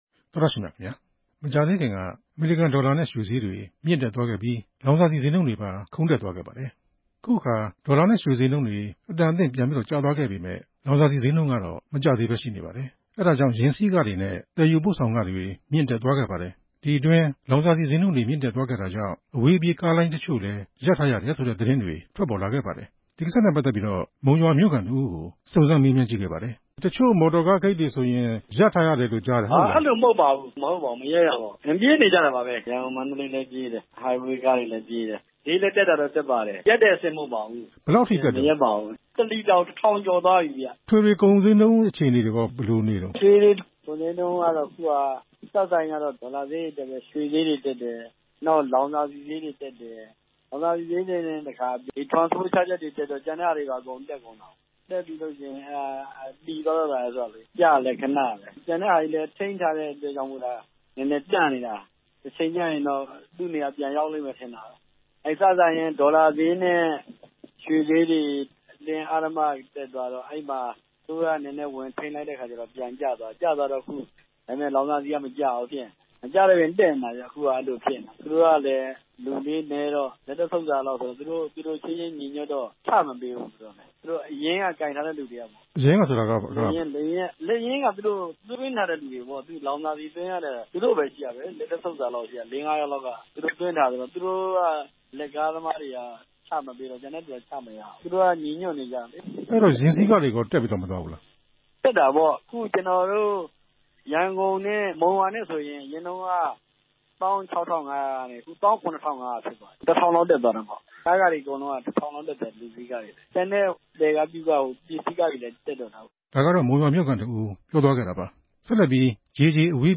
လောင်စာဆီဈေးနှုန်း မကျတဲ့ကိစ္စ ဆက်သွယ်မေးမြန်းချက်